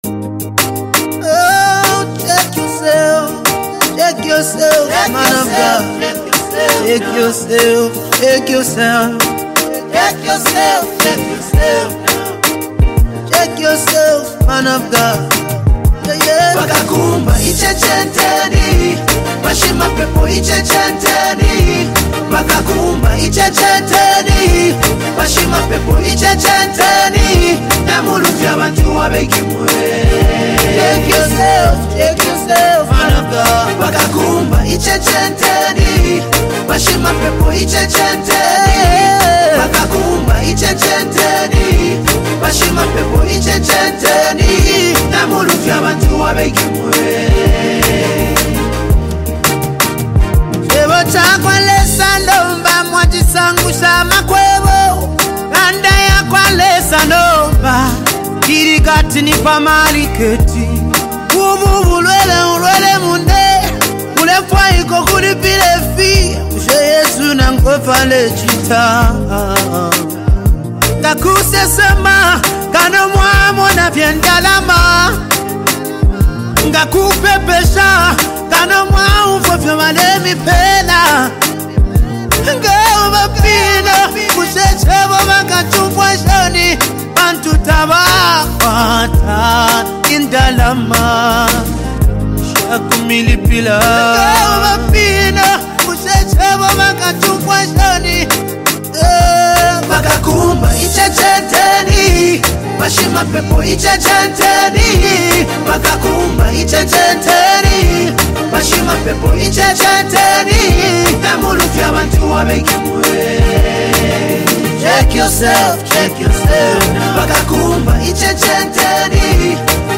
WORSHIP SONG
With his POWERFUL VOICE
ZAMBIAN GOSPEL MUSIC